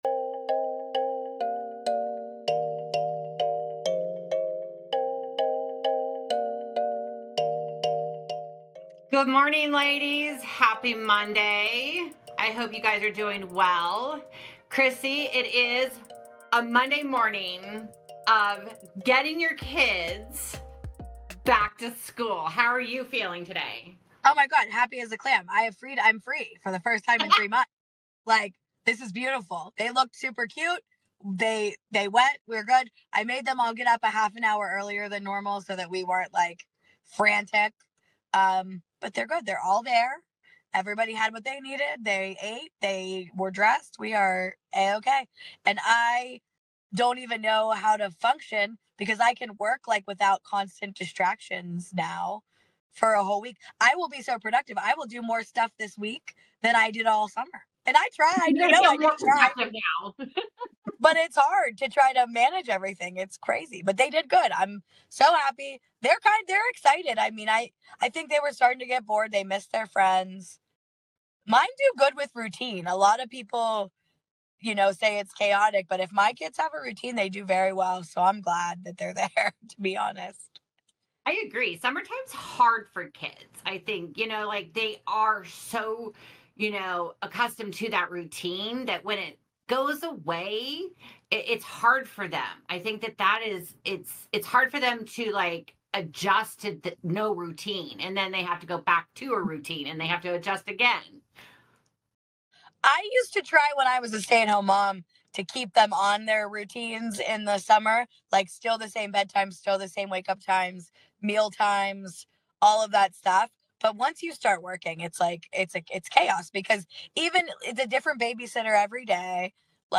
Every other week our group of moms gather to share our stories, tips, and insights on managing the unique challenges that come with parenting our kiddos.